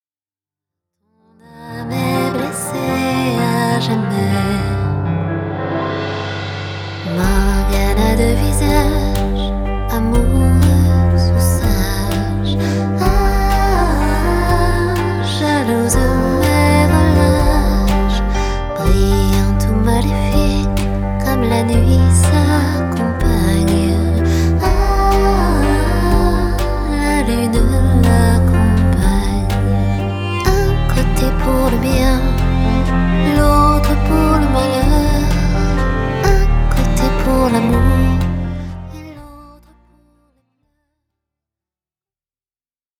harpiste chanteuse